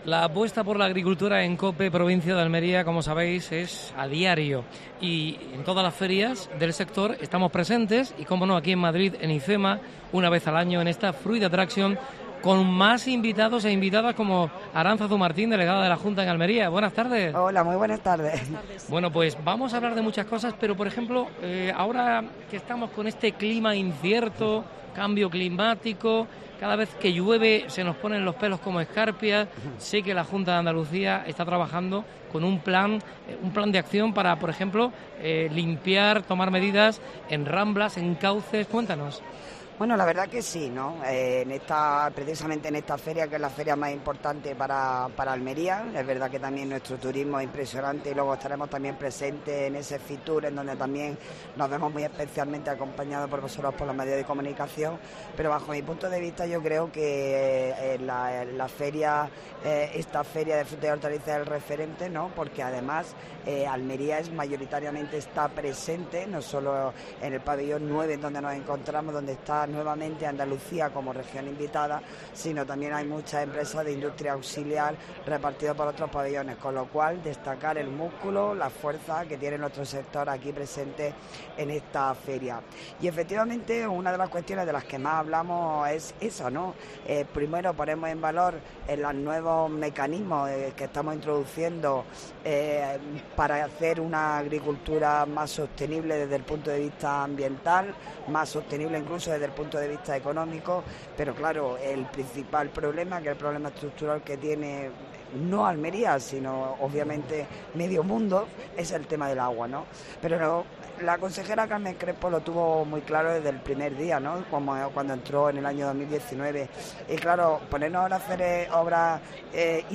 Especial Fruit Attraction desde IFEMA (Madrid). Entrevista a Aránzazu Martín (delegada de la Junta de Andalucía en Almería).